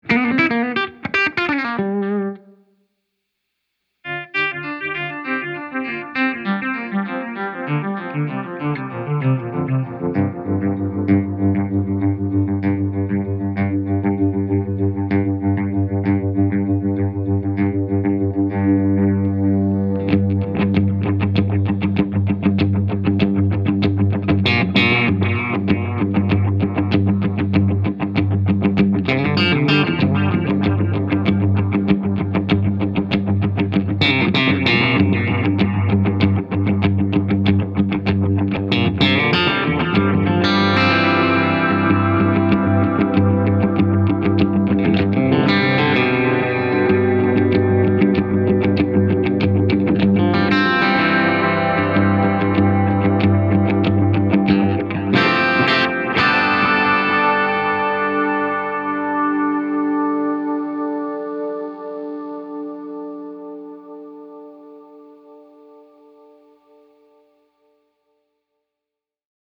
Saturated repeats, somewhat aged tape and high feedback create a classic 70s vibe.